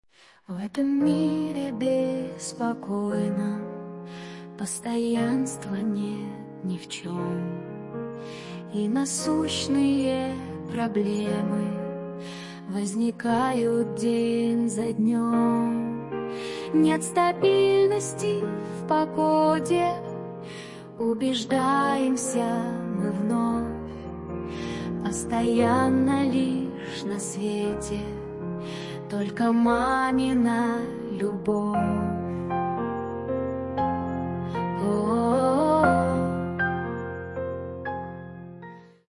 Фрагмент исполнения 2-го варианта (спокойный)